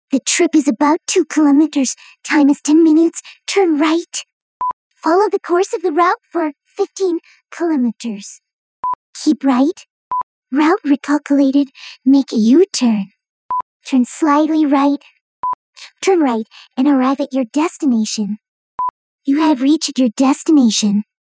Нашёл TTS с голосами поней.